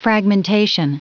Prononciation du mot fragmentation en anglais (fichier audio)
Prononciation du mot : fragmentation